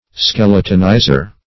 Meaning of skeletonizer. skeletonizer synonyms, pronunciation, spelling and more from Free Dictionary.
Search Result for " skeletonizer" : The Collaborative International Dictionary of English v.0.48: Skeletonizer \Skel"e*ton*i`zer\, n. (Zool.)